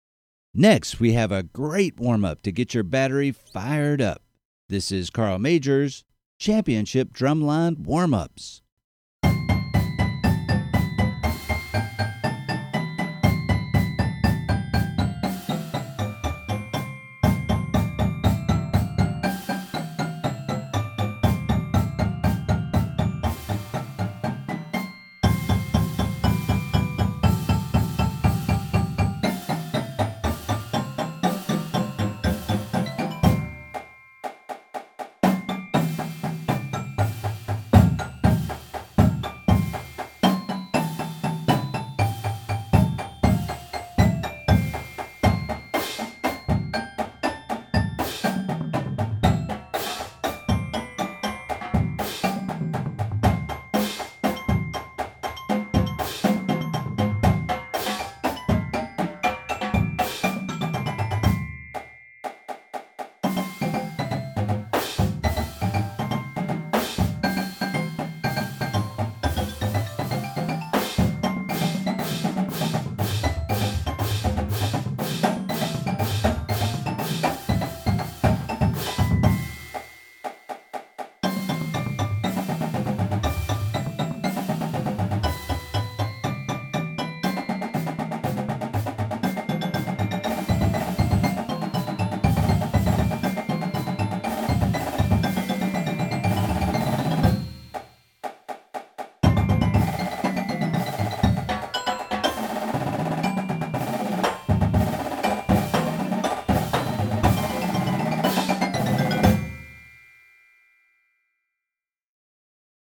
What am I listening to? Voicing: Percussion Feature